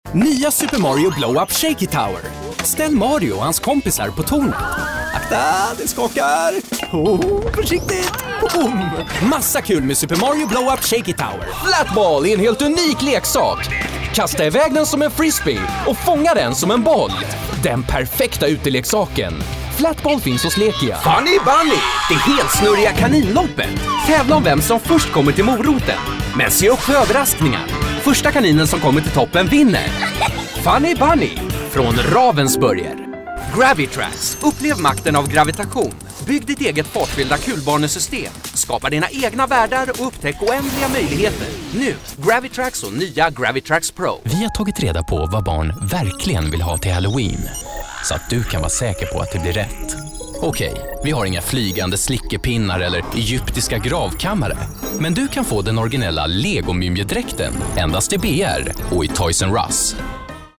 Male
Authoritative, Confident, Corporate, Engaging, Friendly, Warm, Young, Conversational, Energetic, Upbeat
English with Swedish/Scandinavian accent.
Microphone: Neumann TLM-102
Audio equipment: Professional Sound Booth from Demvox, ECO100